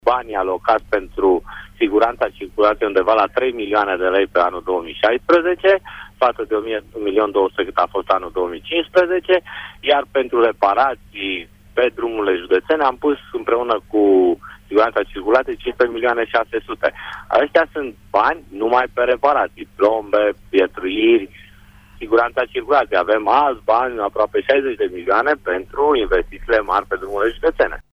Anul acesta au fost alocate 3 milioane de lei pentru siguranța rutieră din bugetul judetului Brașov, față de 1,2 milioane cât a fost bugetul anului trecut, a explicat vicepreședintele Consiliului Județean Adrian Gabor: